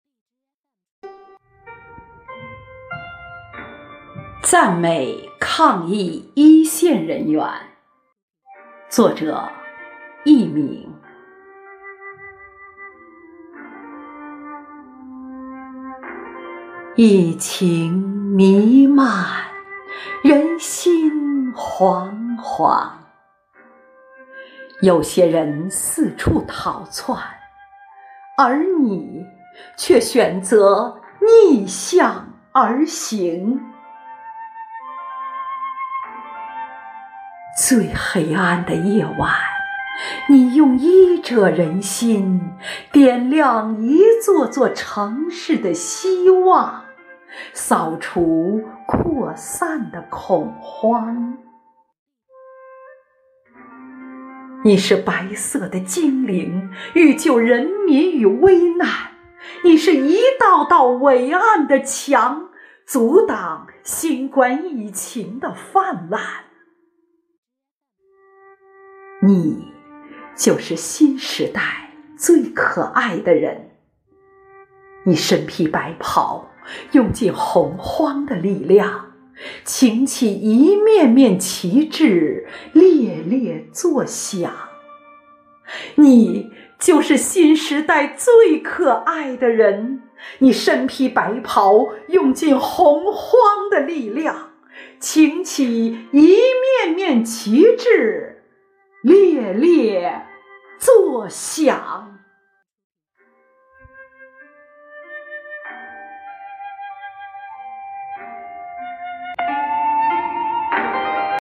暨中华诗韵支队第13场幸福志愿者朗诵会